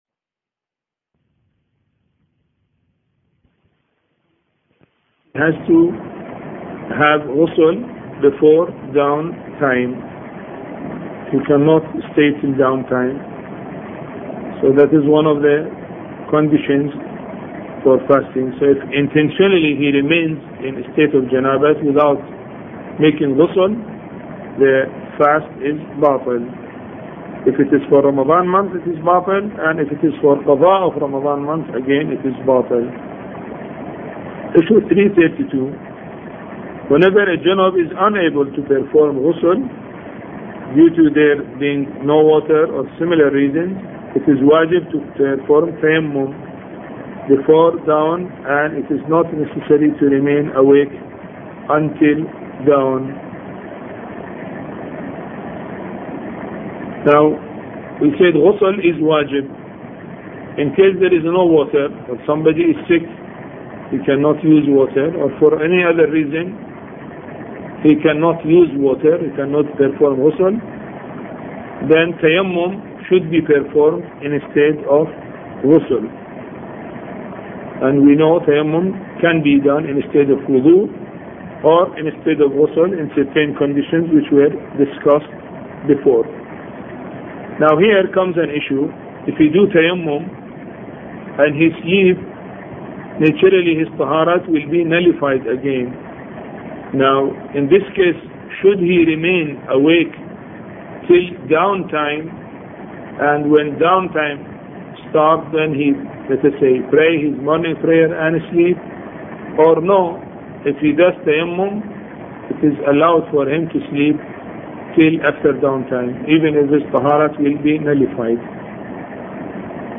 A Course on Fiqh Lecture 25